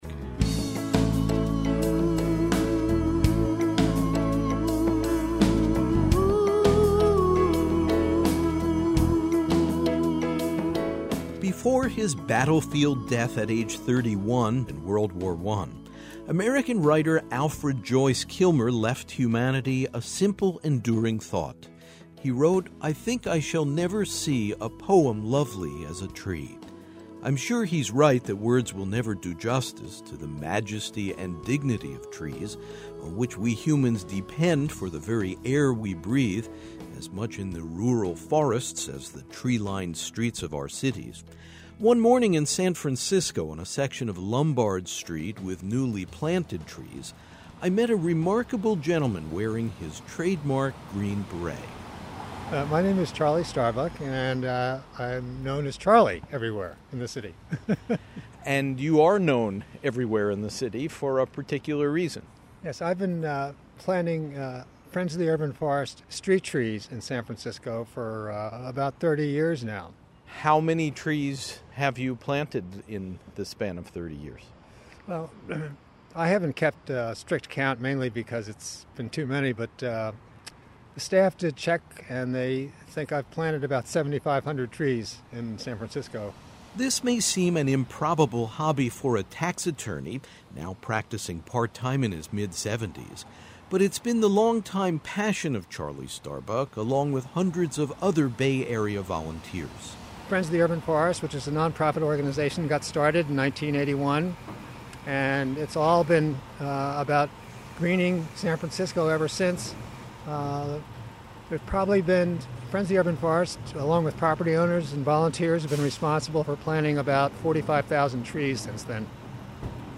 Tree-Lined Streets — Humankind on Public Radio